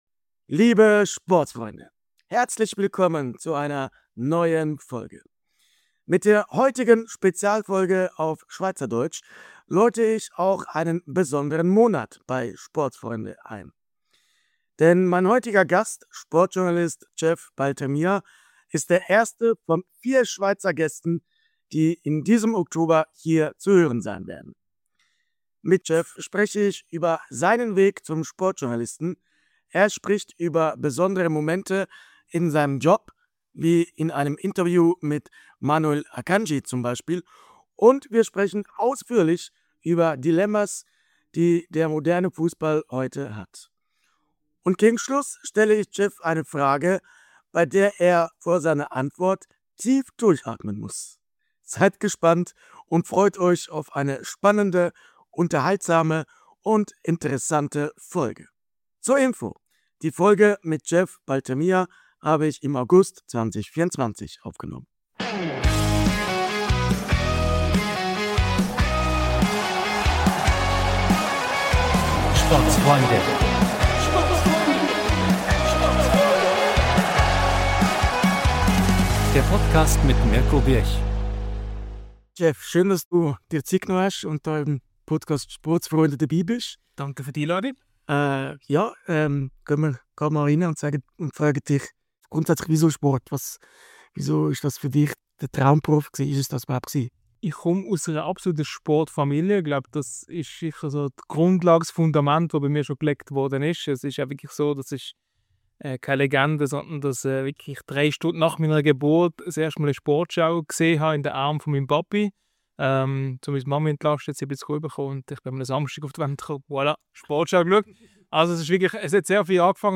Liebe Sportsfreunde, herzlich willkommen zu dieser Spezialfolge auf Schweizerdeutsch!